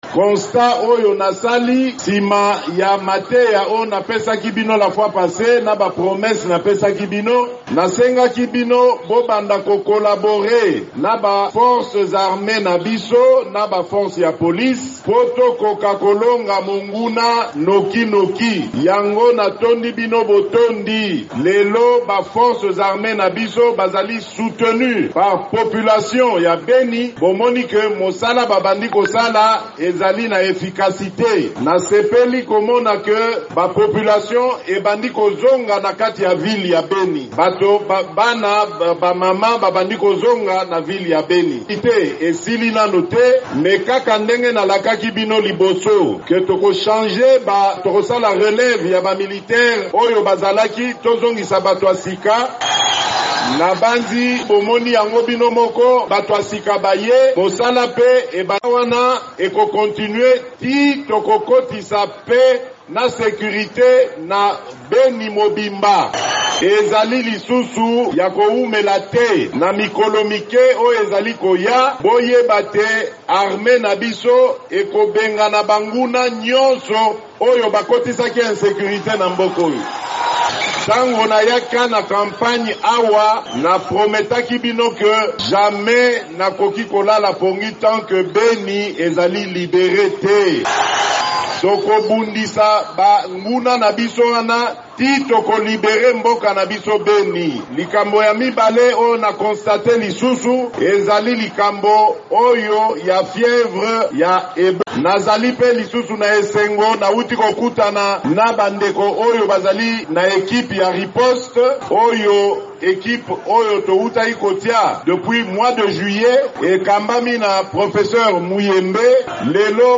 Président Félix Tshisekedi alaki, na meeting na ye liboso bituluku na esika ya 30 juin na Beni, ete mampinga ya FARDC bakobengana batomboki mpe basimba minduki na RDC kala mingi te. Alobi nzela ebandi kobongisama mpo malona mazonga na Beni. Asepeli ndenge Ebola ezali kosila.